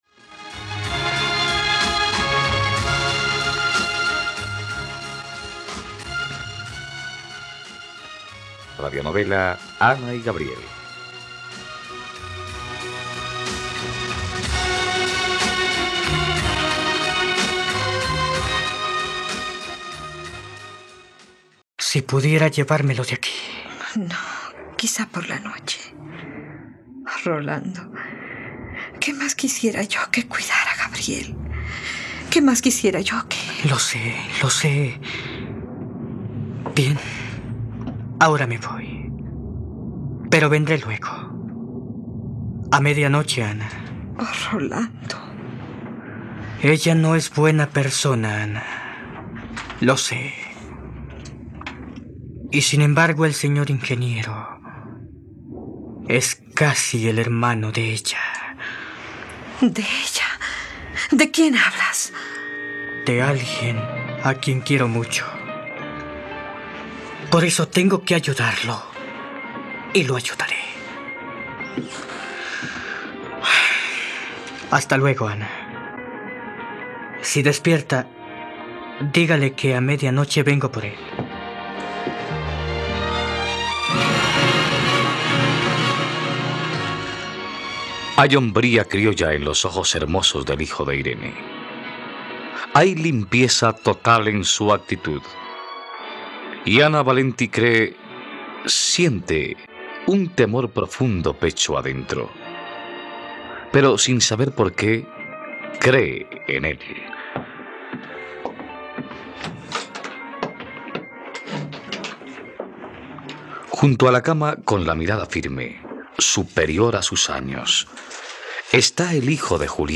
..Radionovela. Escucha ahora el capítulo 62 de la historia de amor de Ana y Gabriel en la plataforma de streaming de los colombianos: RTVCPlay.